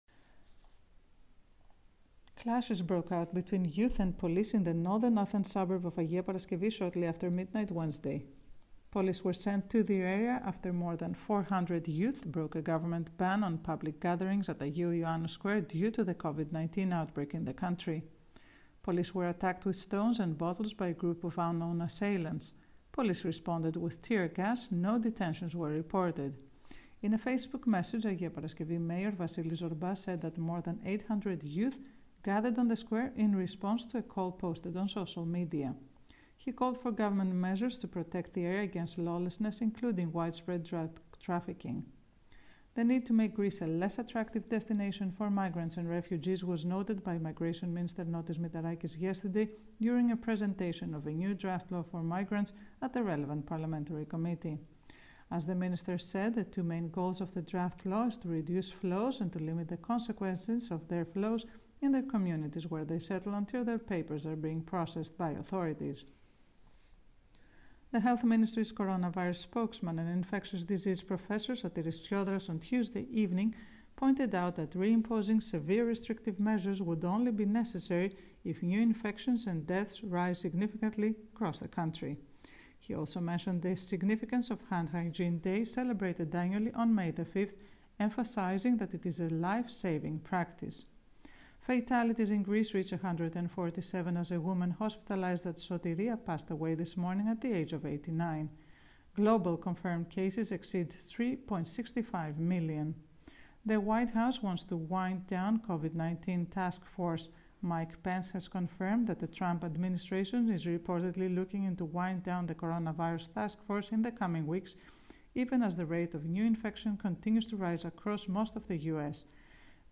News in brief